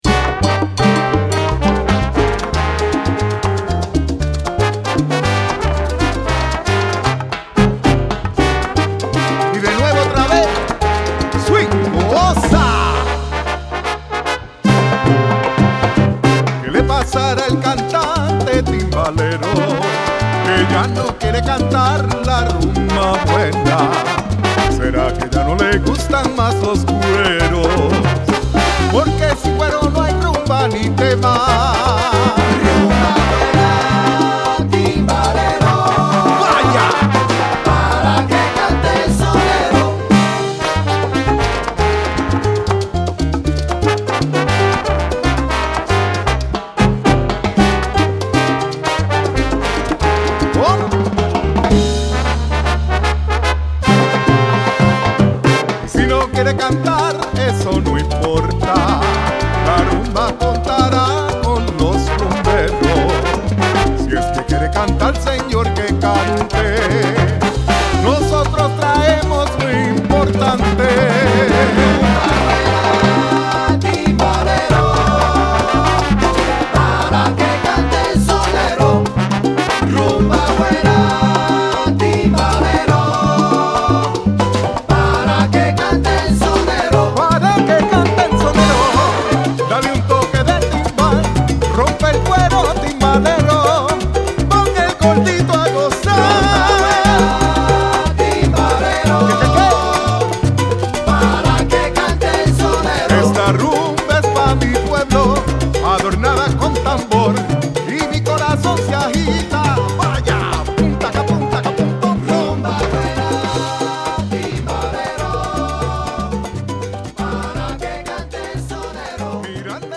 この商品に関連するキーワード Japanese Latin